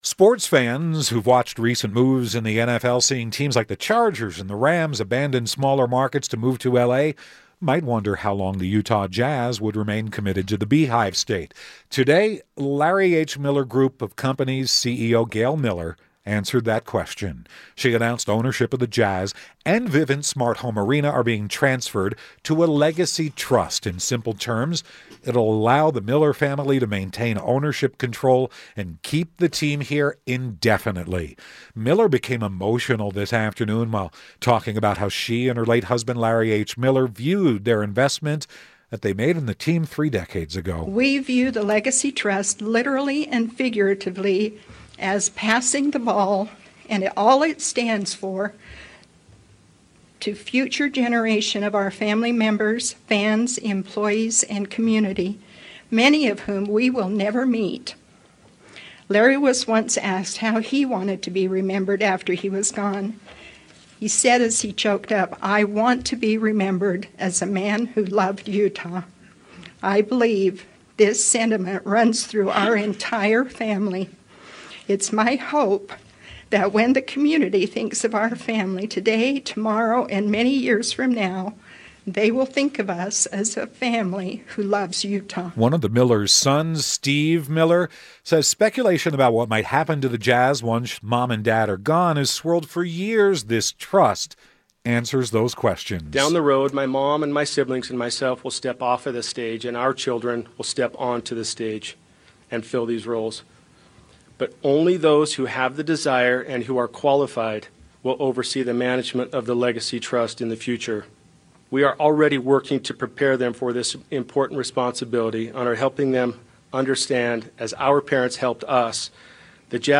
during a press conference on Monday.